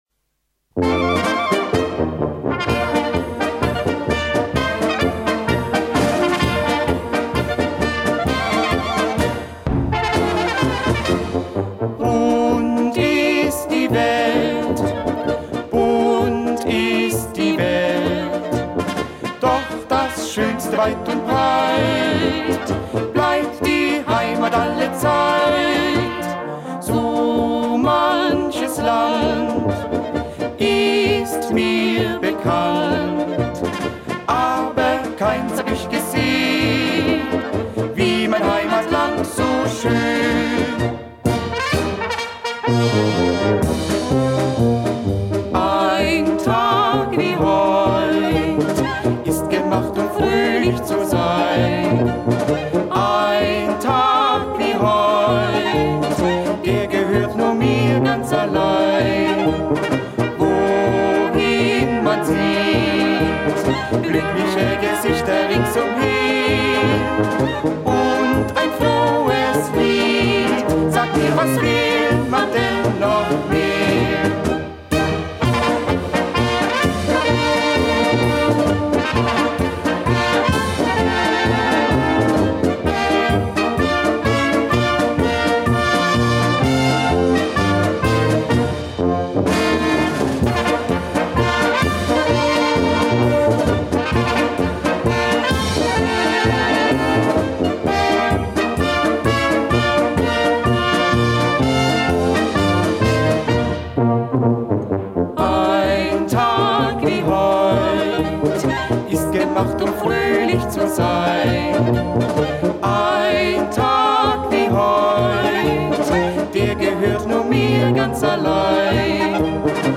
Polka mit Gesang